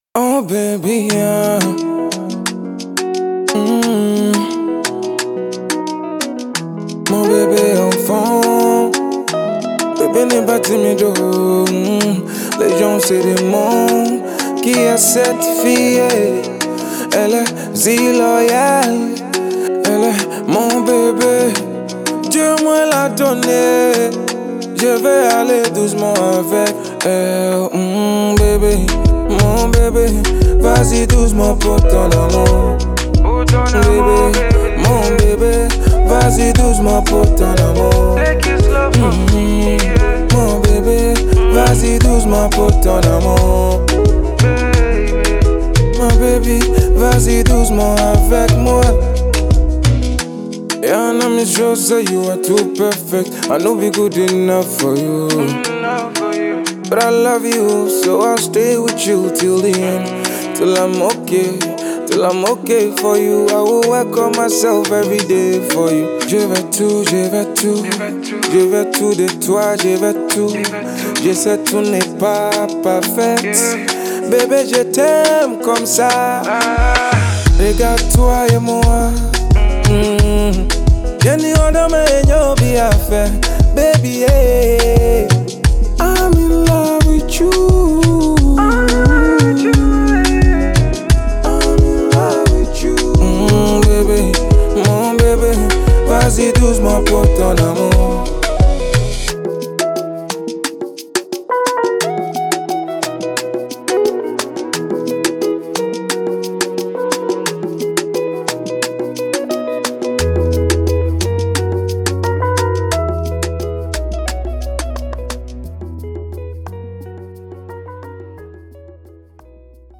Ghanaian highlife singer
soothing romance and rich melodies